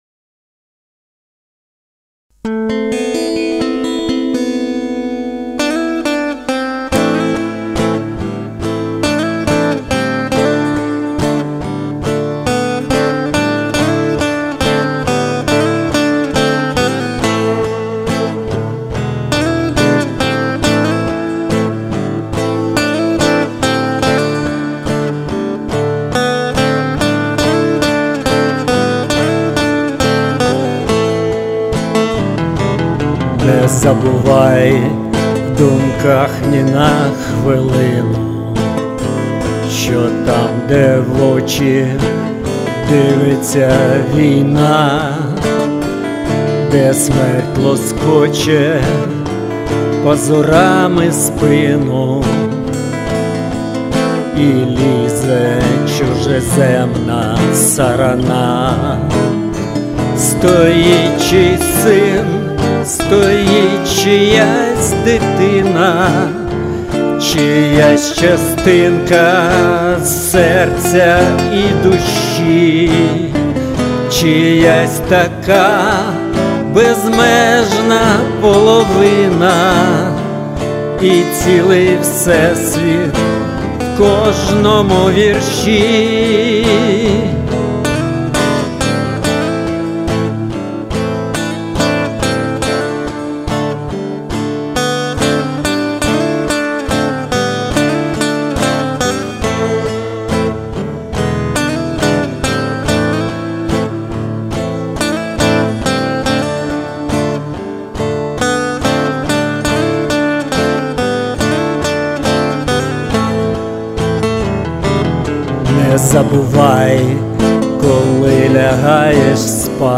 Пісні АТО